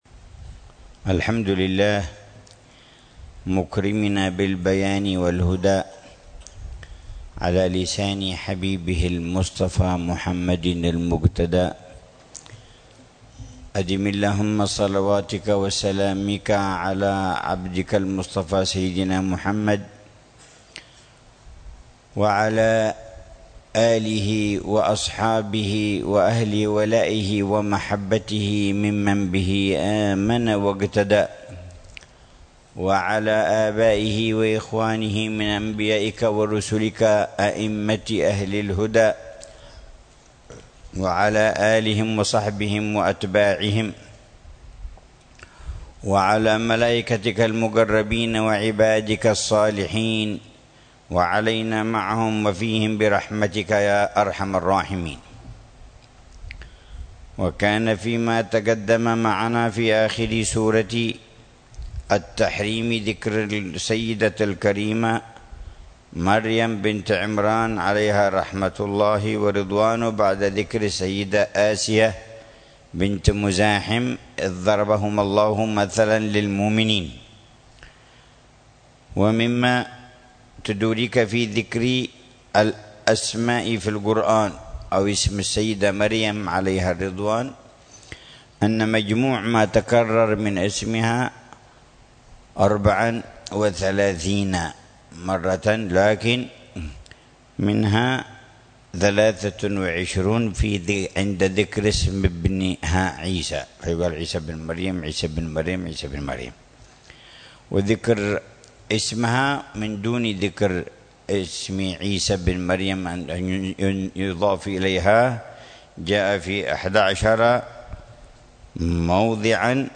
تفسير الحبيب العلامة عمر بن محمد بن حفيظ للآيات الكريمة من سورة الطلاق، ضمن الدروس الصباحية لشهر رمضان المبارك لعام 1441، من قوله تعالى: